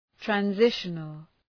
Προφορά
{træn’zıʃənəl}
transitional.mp3